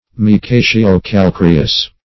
Search Result for " micaceo-calcareous" : The Collaborative International Dictionary of English v.0.48: Micaceo-calcareous \Mi*ca`ce*o-cal*ca"re*ous\, a. (Geol.) Partaking of the nature of, or consisting of, mica and lime; -- applied to a mica schist containing carbonate of lime.
micaceo-calcareous.mp3